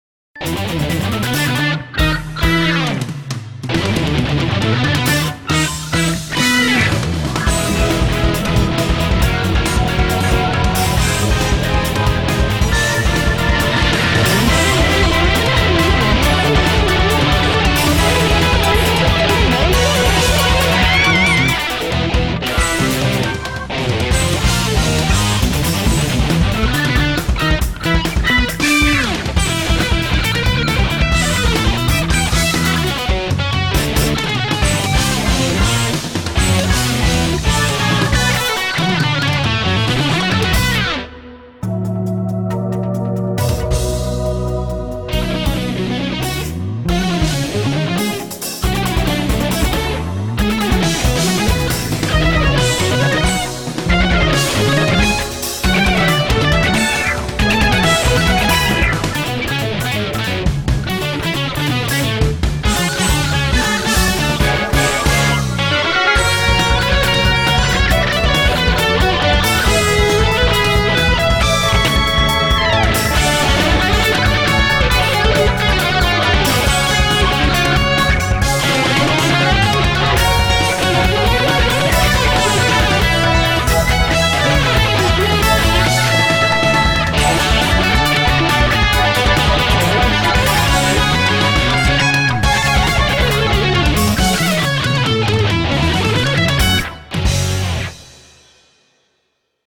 BPM137
Watch out for the sudden stops!